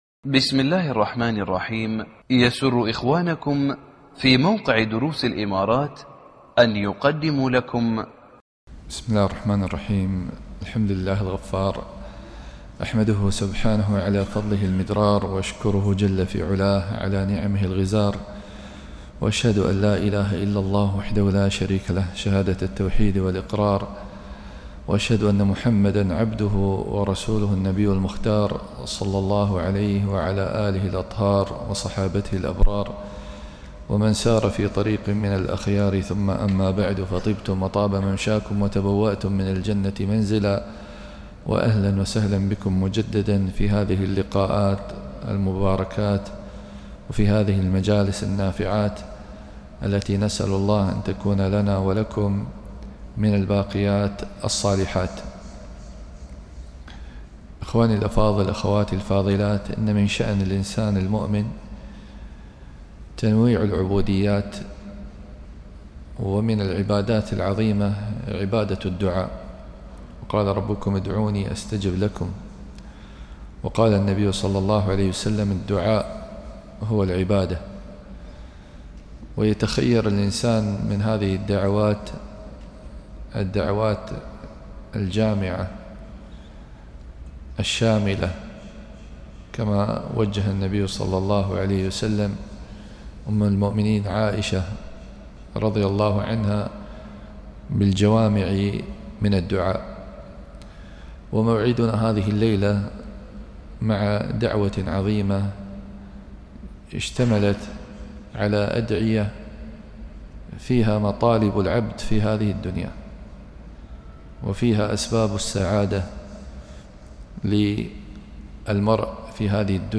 محاضرة بعنوان